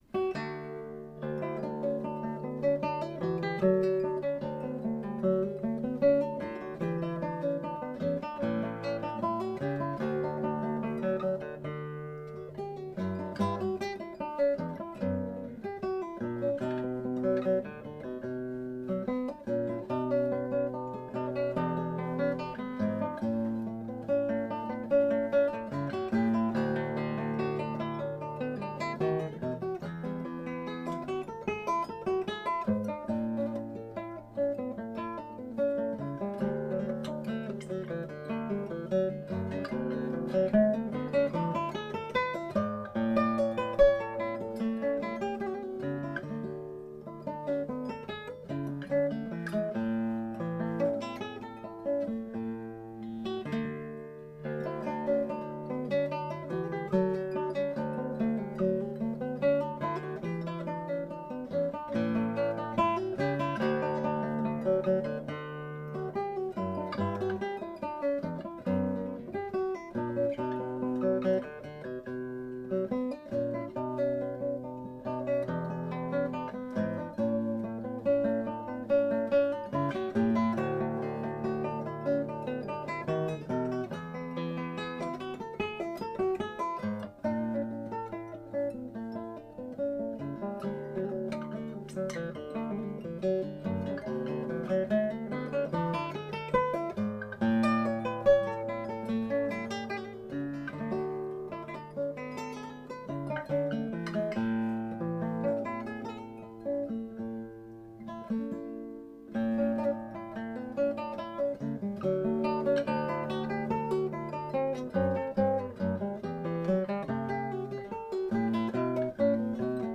Top: Hasselfichte Spruce
Back and Sides: East Indian Rosewood
Hear this guitar